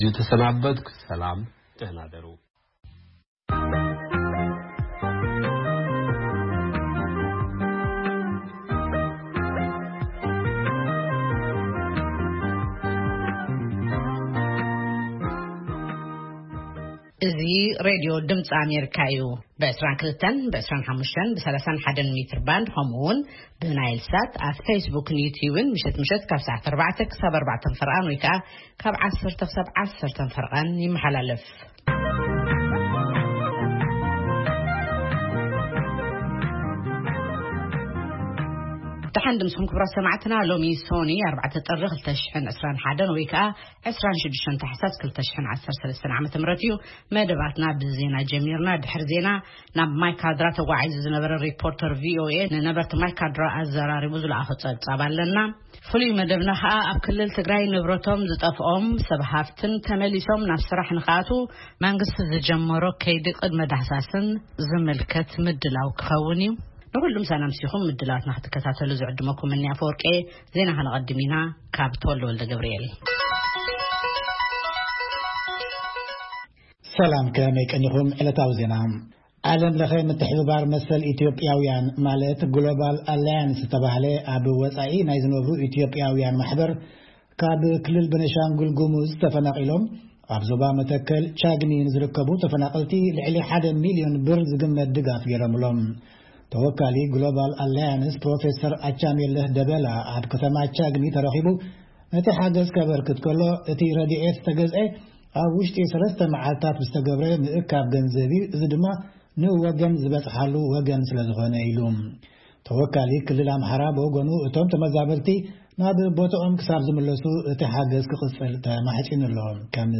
ፈነወ ትግርኛ ብናይ`ዚ መዓልቲ ዓበይቲ ዜና ይጅምር ። ካብ ኤርትራን ኢትዮጵያን ዝረኽቦም ቃለ-መጠይቓትን ሰሙናዊ መደባትን ድማ የስዕብ ። ሰሙናዊ መደባት ሰኑይ፡ ሳይንስን ተክኖሎጂን / ሕርሻ